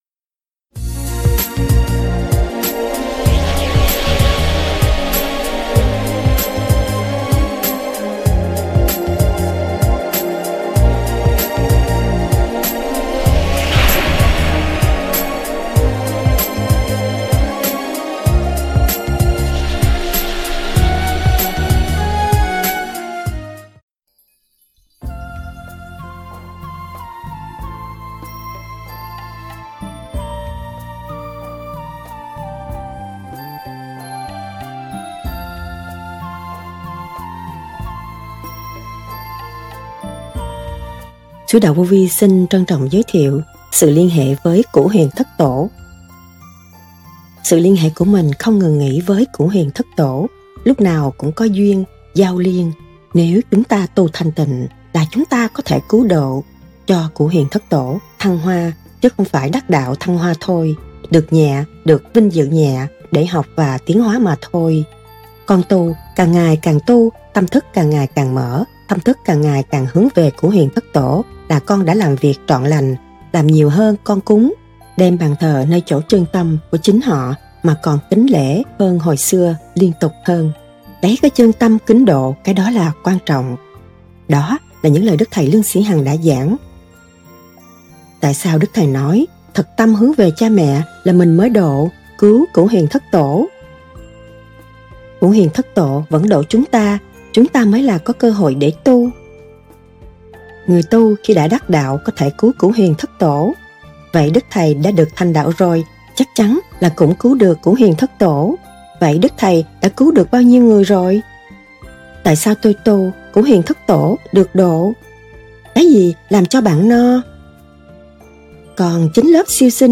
SỰ LIÊN HỆ VỚI CỬU HUYỀN THẤT TỔ- Lời giảng